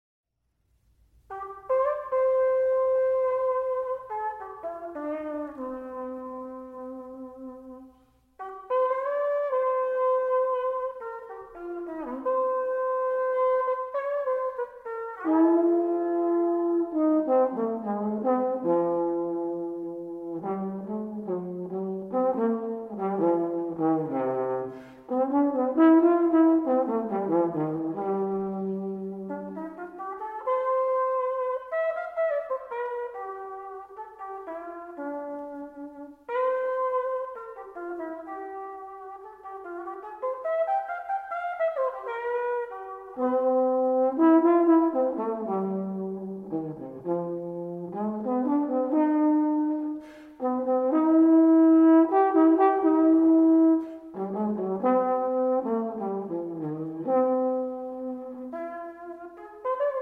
A Renaissance Band
sackbut, recorders
cornetto, gemshorns, recorders
percussion, viol, recorders
vielle, viols